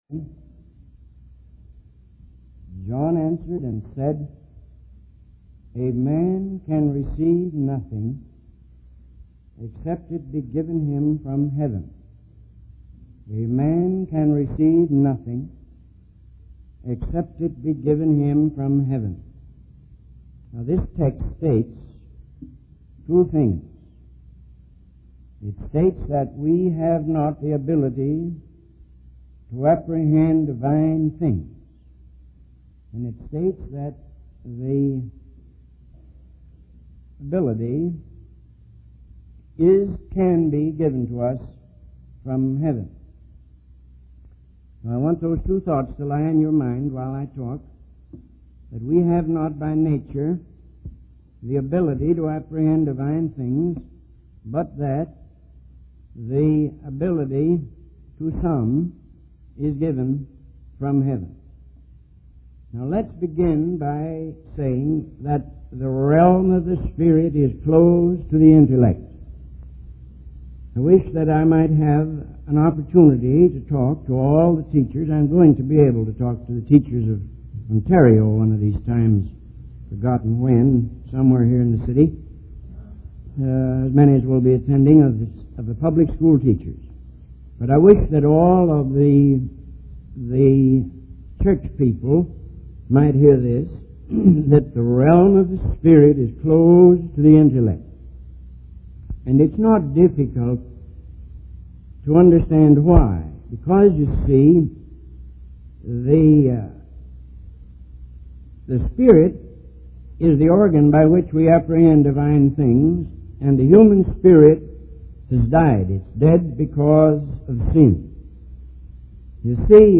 In this sermon, the preacher emphasizes the importance of obeying God and following the teachings of the scriptures.